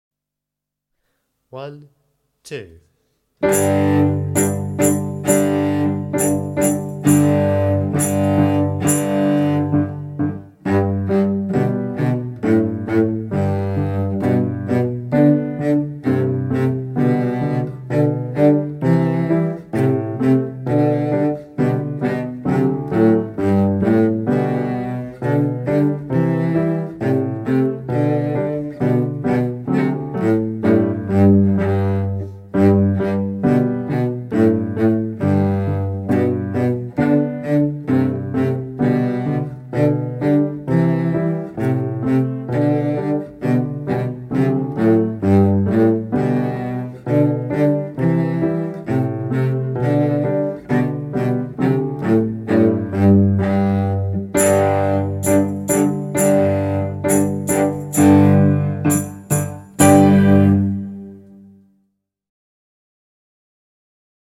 35 Stamping dance (Cello)